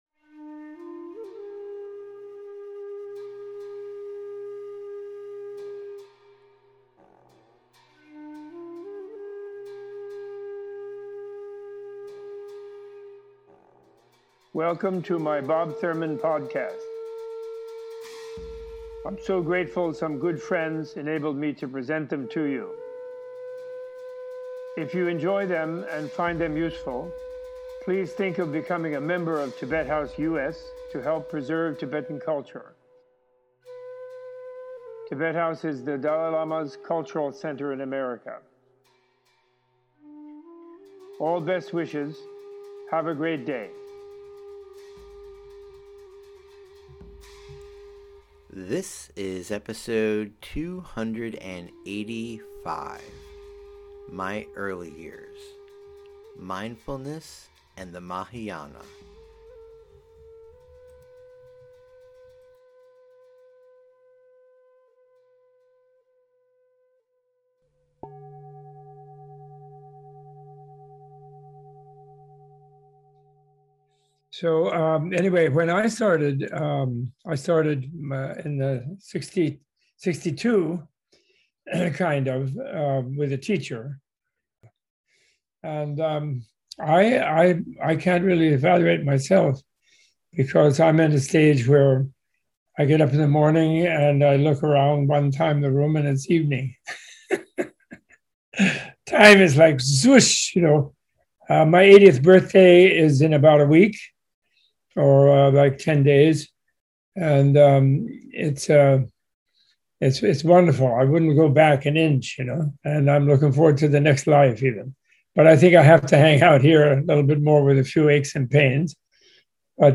Recounting his early days studying with Geshe Ngawang Wangyal, Robert Thurman in this episode gives a teaching on practicing meditation and the Buddhist perspective on mindfulness through a close line reading of the The Mahāsatipatthāna Sutta. The four focuses of mindfulness in the sutta are the body, the sensations, the mind, and the mental objects, among which the last is the longest and most elaborated.